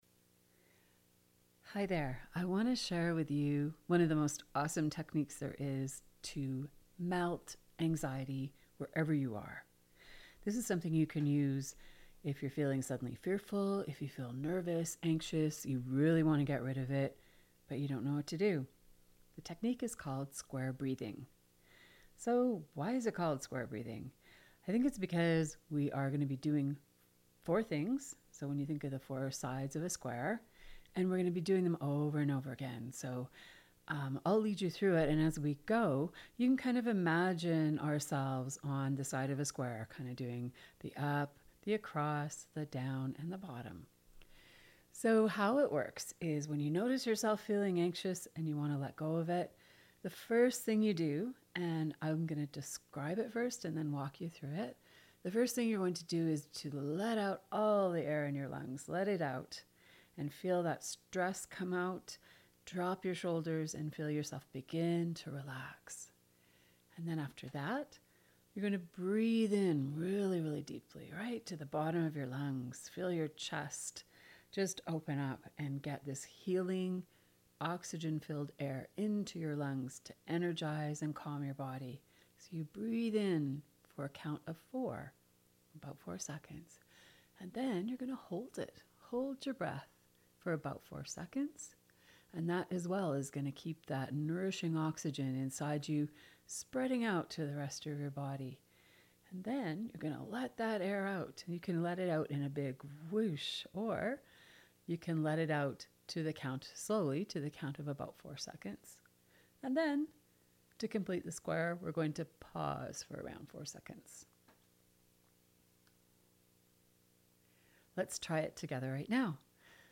Listen to Gentle soothing square breathing instructions here